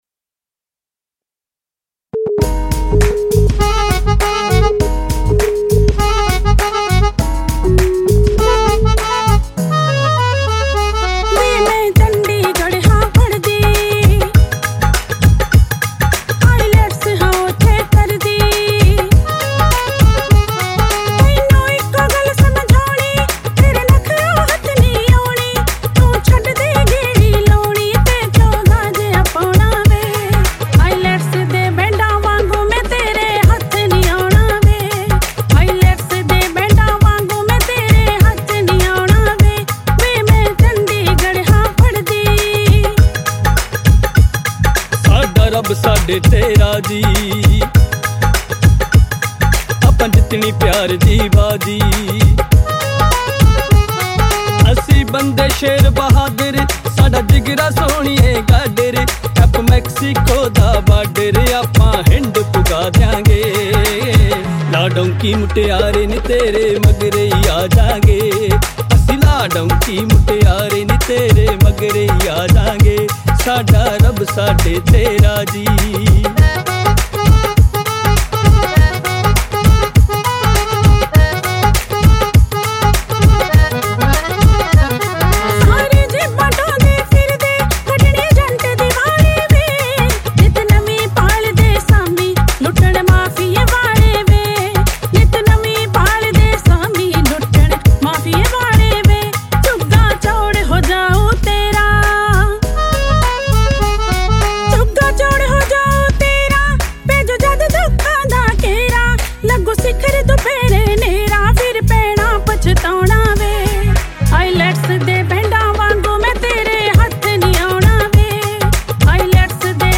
Category: Single Songs